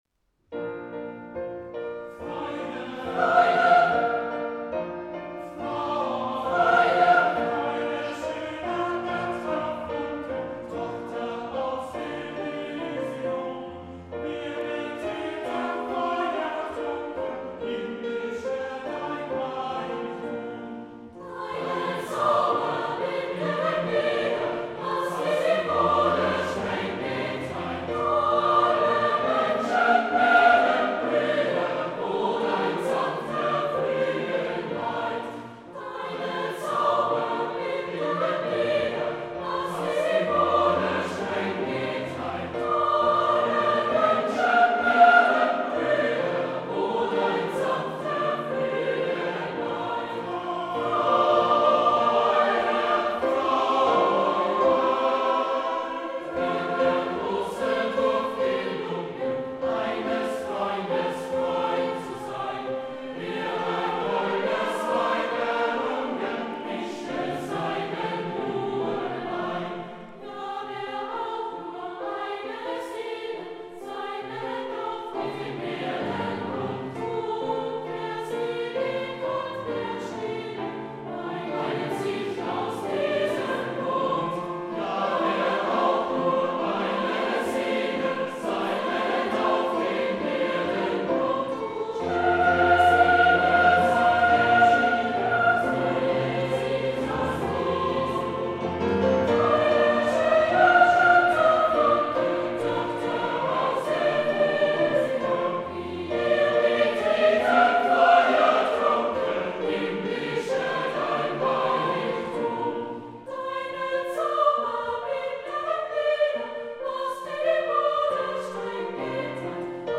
School Choir "Grazer Keplerspatzen"
Meanwhile, girls' and men's voices were added to the choir, which have given rise to the characteristic sound that is typical of the “Kepler Sparrows”.
Keplerspatzen singing "Ode to joy" by Ludwig van Beethoven